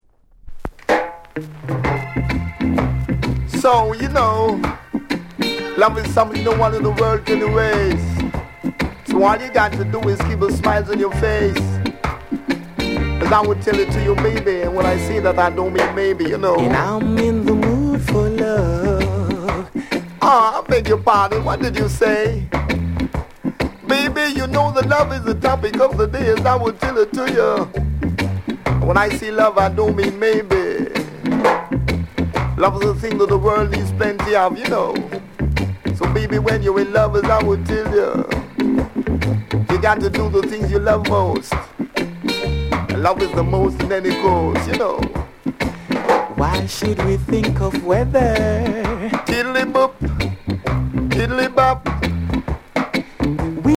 NICE DEE JAY CUT